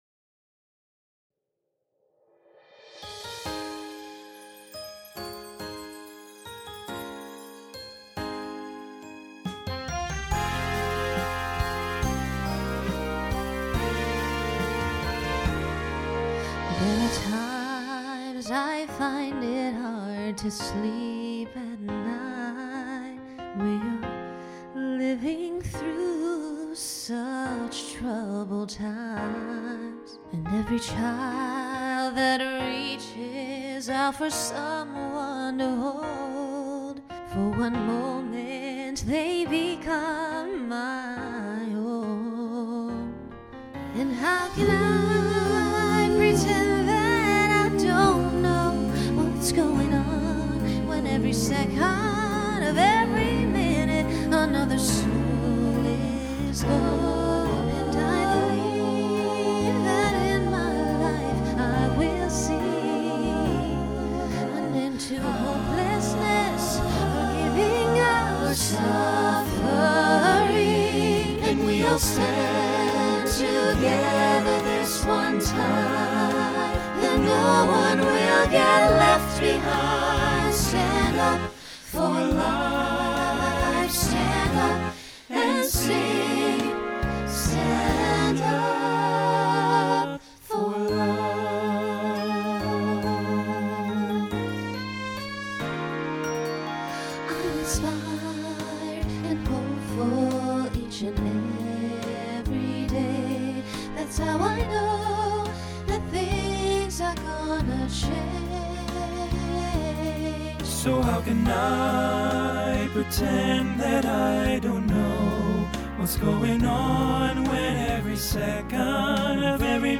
Voicing SATB Instrumental combo Genre Pop/Dance
Ballad